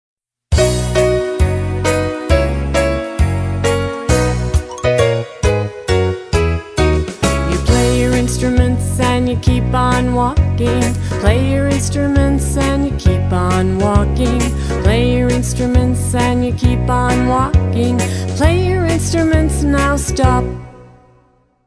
(Repeat and get faster)
Action Song Lyrics